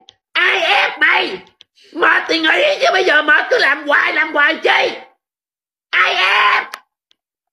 Viral saying oh my god... sound effects